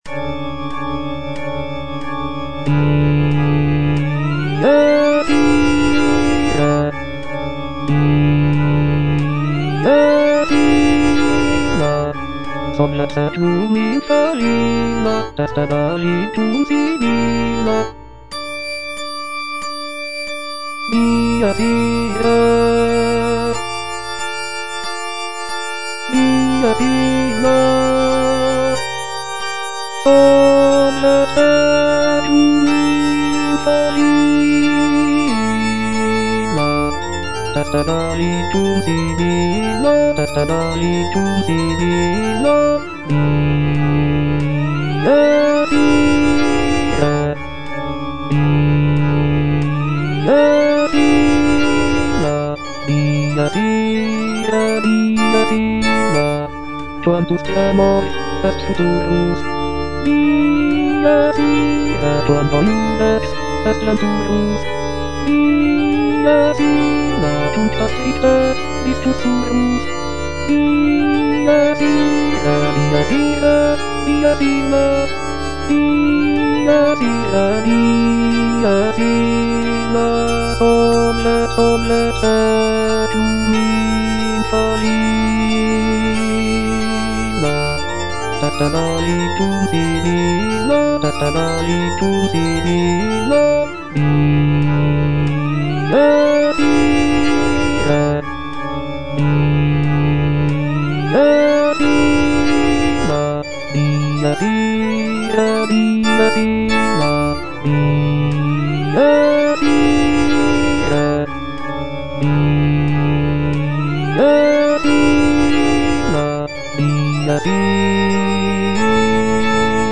F. VON SUPPÈ - MISSA PRO DEFUNCTIS/REQUIEM Dies irae - Bass (Voice with metronome) Ads stop: auto-stop